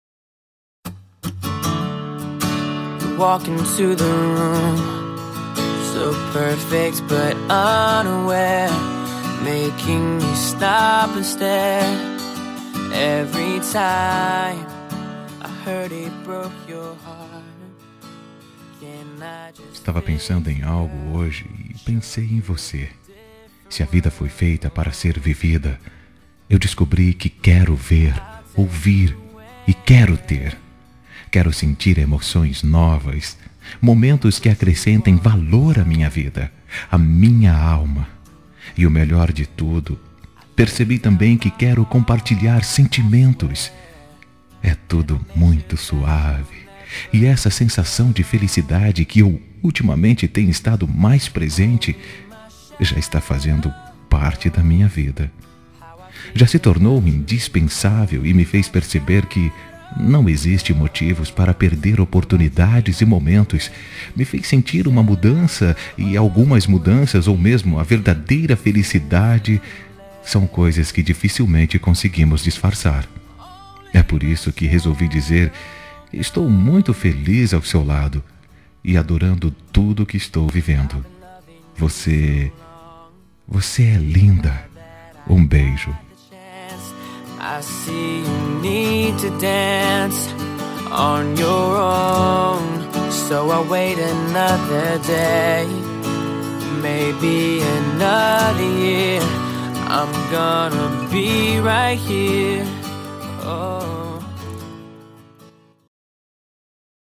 Telemensagem Início de Namoro – Voz Masculina – Cód: 754